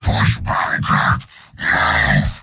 Giant Robot lines from MvM. This is an audio clip from the game Team Fortress 2 .
{{AudioTF2}} Category:Heavy Robot audio responses You cannot overwrite this file.
Heavy_mvm_m_cartmovingforwardoffense16.mp3